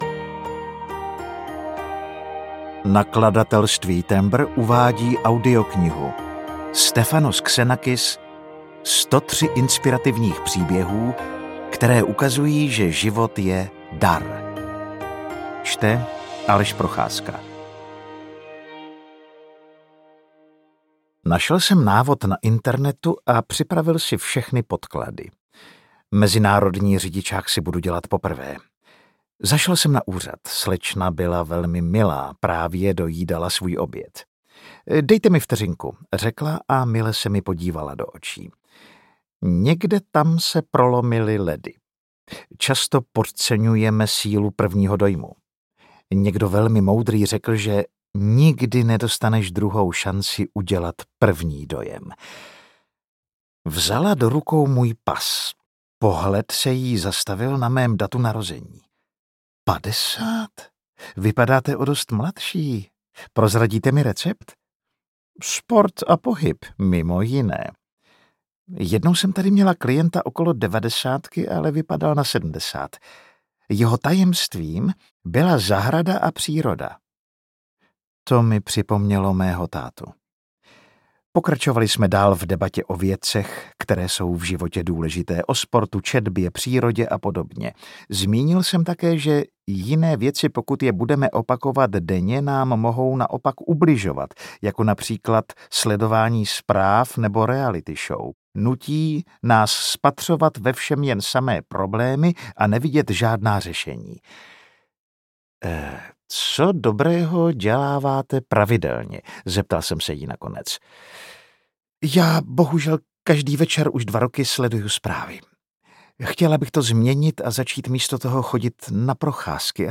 103 inspirativních příběhů, které ukazují, že život je DAR audiokniha
Ukázka z knihy